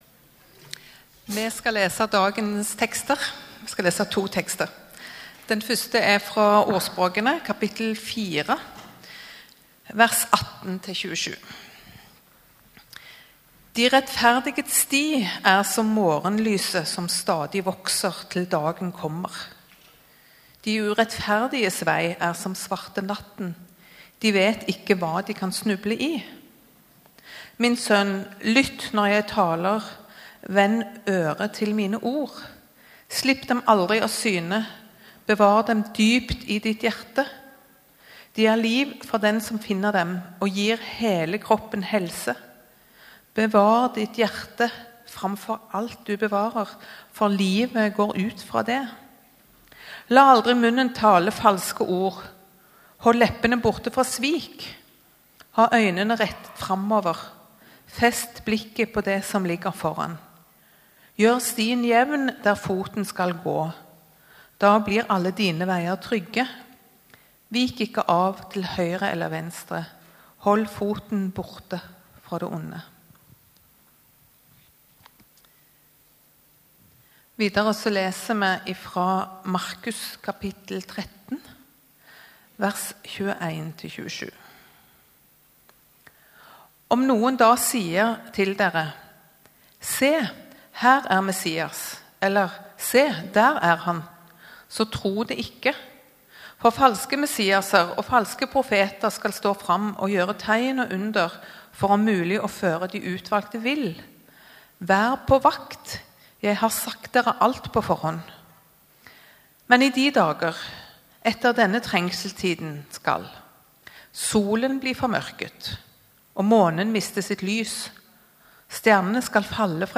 Gudstjeneste 6. februar 2022, -Menneskesønnen komm | Storsalen